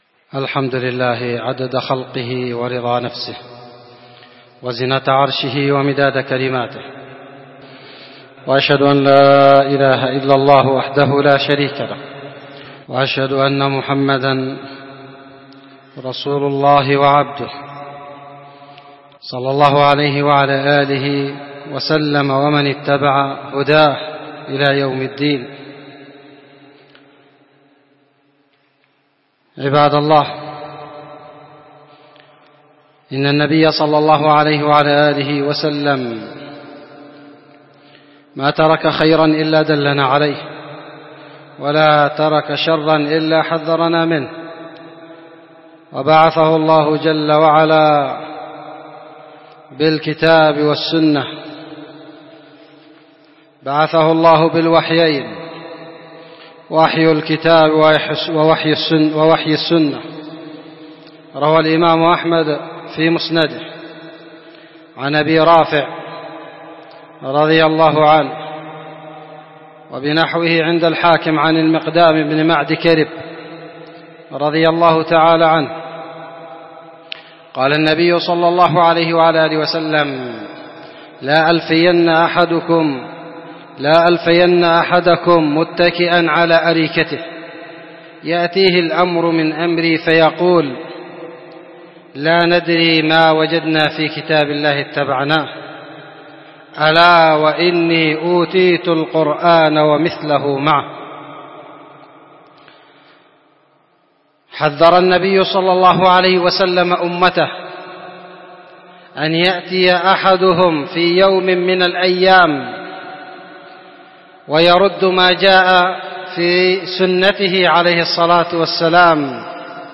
خطبه جمعه